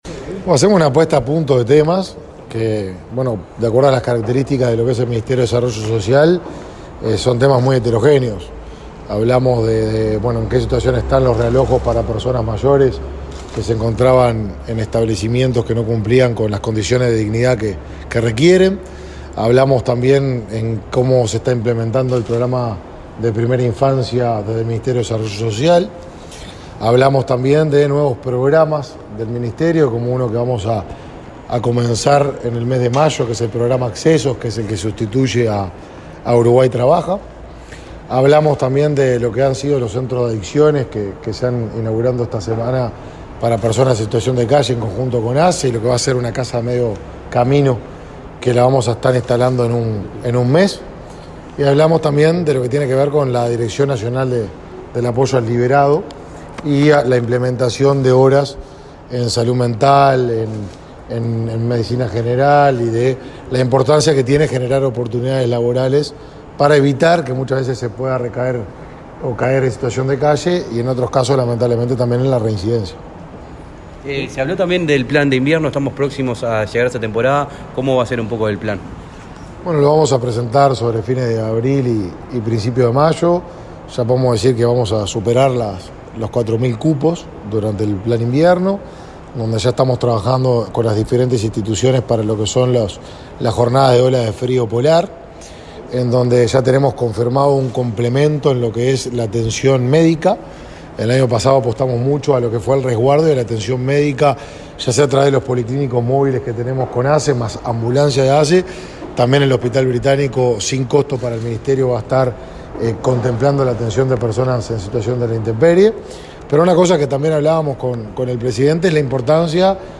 Declaraciones a la prensa del ministro de Desarrollo Social, Martín Lema
Declaraciones a la prensa del ministro de Desarrollo Social, Martín Lema 06/04/2022 Compartir Facebook X Copiar enlace WhatsApp LinkedIn En el marco de los acuerdos ministeriales, el titular de Desarrollo Social, Martín Lema, se reunió este miércoles 6 con el presidente Lacalle Pou y, luego, dialogó con la prensa.